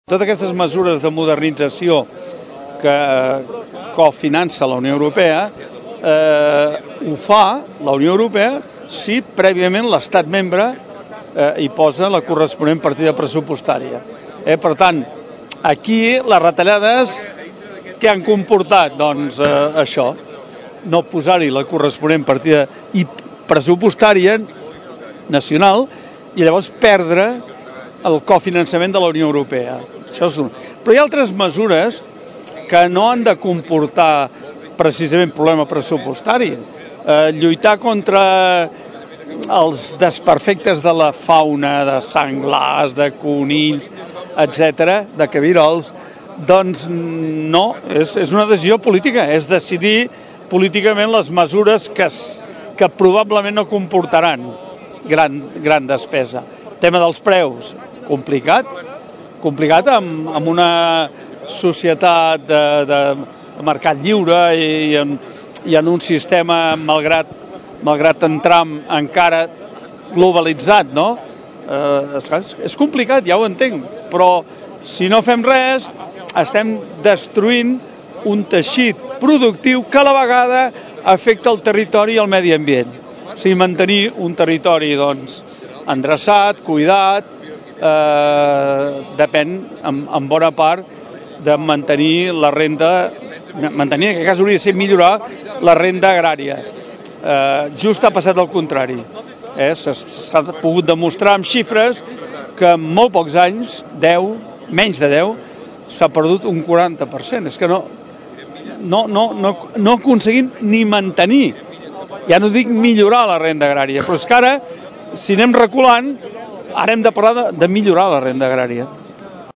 Amb aquesta mobilització, Unió de Pagesos vol reivindicar la dignitat del camp català i el reconeixement de l’activitat agrària, a la vegada que considera que ha de servir per renovar el contracte de la societat amb el camp en la conjuntura de canvi social i polític actual. Ho explica en declaracions a Ràdio Tordera